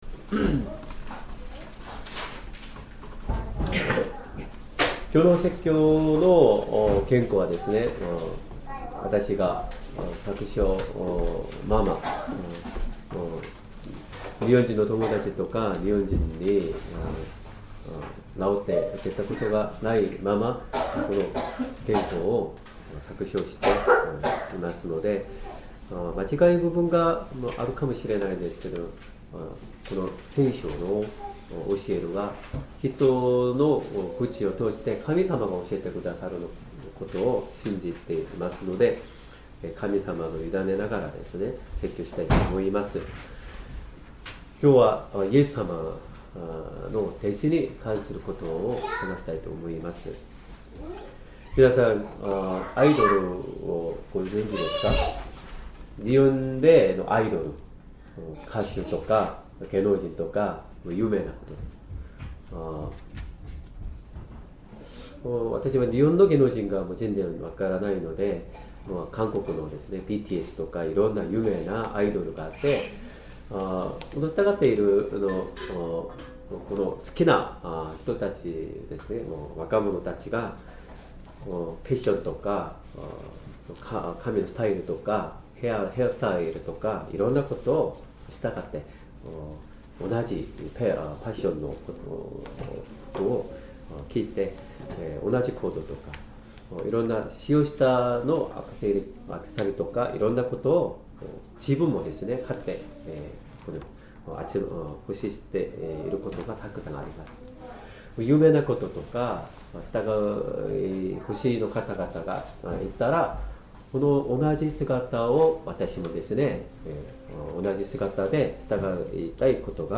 Sermon
2025年2月16日 主日礼拝 説教 「主に従う信仰」 聖書 マタイの福音書８：１６～２２ 8:16 夕方になると、人々は悪霊につかれた人を、大勢みもとに連れて来た。